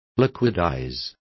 Complete with pronunciation of the translation of liquidizing.